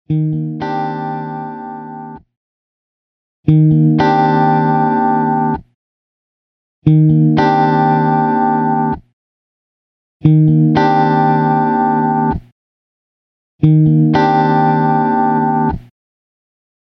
Clean sustaining chord x5. Release settings: Original, 50ms, 500ms, 1 second, 5 seconds.
This particular example is subtle, but if you pay attention you can hear the decay of the chord wanting to die out but not being able to. The 50ms setting is the most musical. Even though the other settings aren’t completely out of control, there are still subtle tell-tale signs of too long a release: very slight wavering, distorting, up and down pumping, etc. They are all very slight, but listen closely and you’ll pick them out!